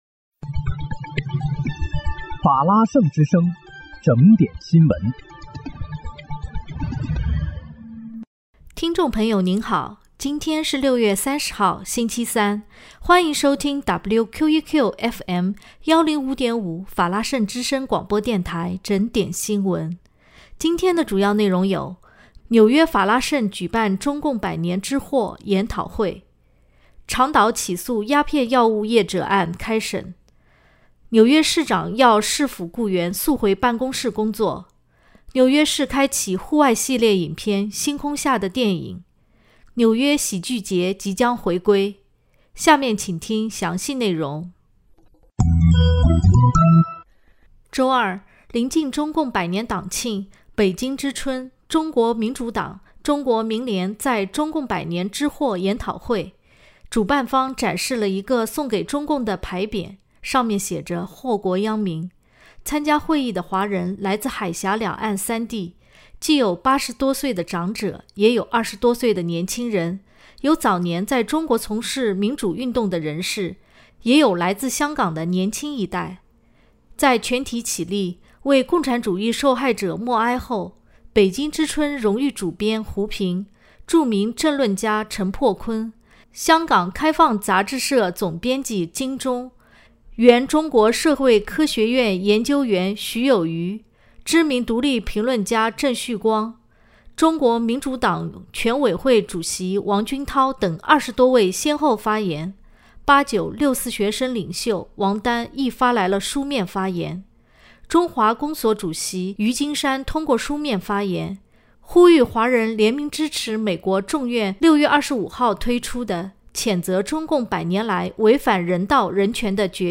6月30日（星期三）纽约整点新闻